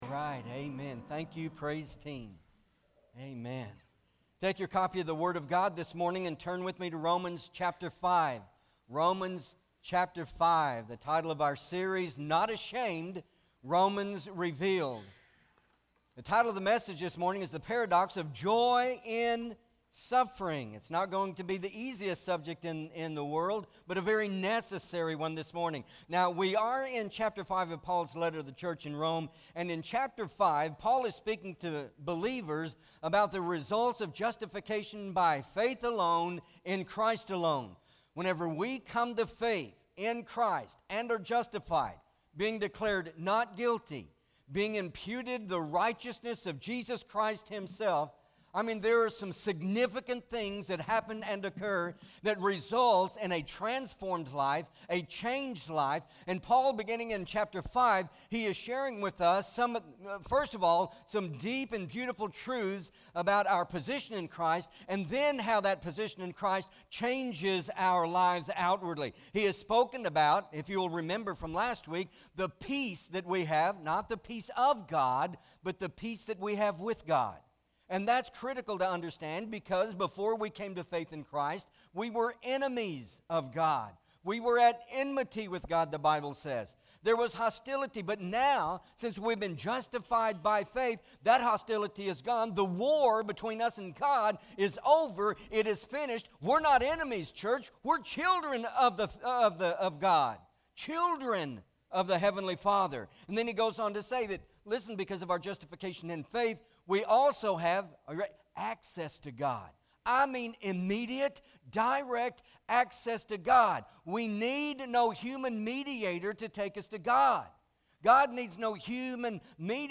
Romans Revealed- The Paradox of Joy in Suffering MP3 SUBSCRIBE on iTunes(Podcast) Notes Sermons in this Series Romans 5: 3-5 Not Ashamed!